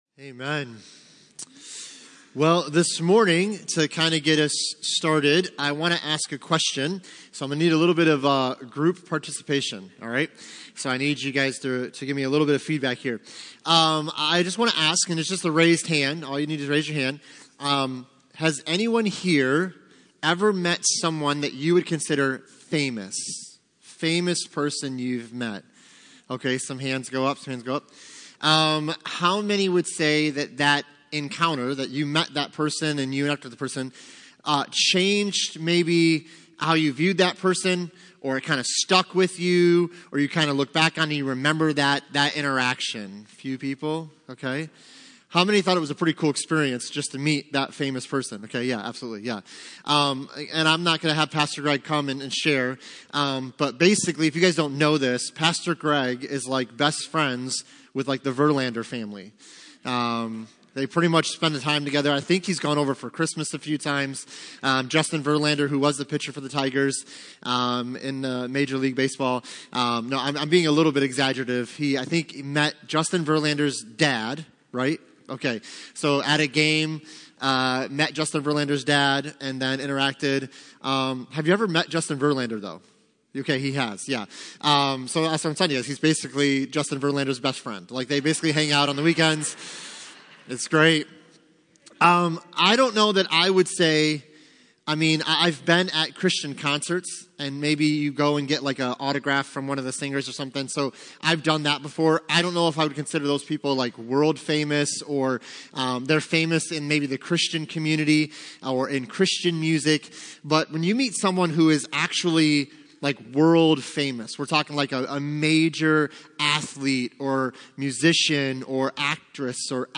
Passage: Acts 9:3-9 Service Type: Sunday Morning